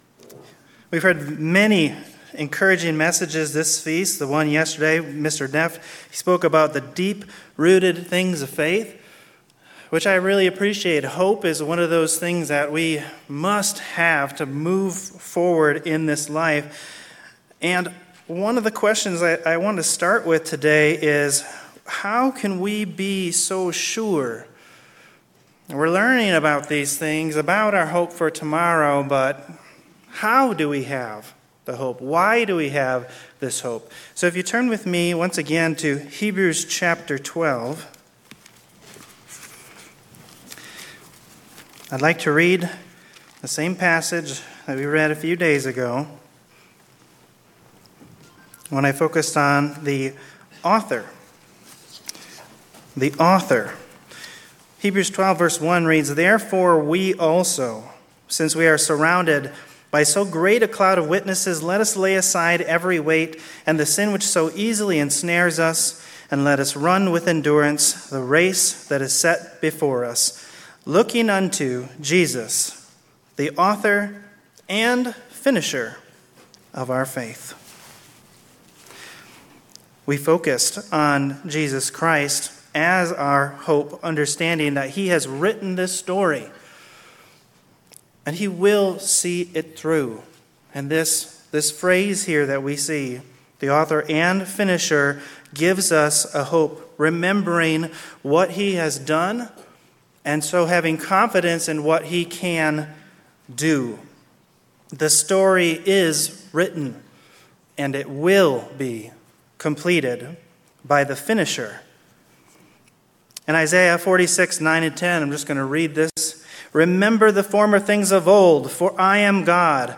Sermons
Given in Klamath Falls, Oregon